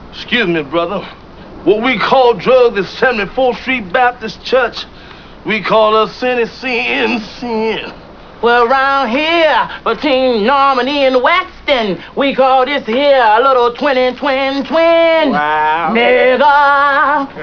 here are some sounds from my favorite movie of all time, friday...you should set them up so they come on when you start and shut down your computer. always good for a laugh.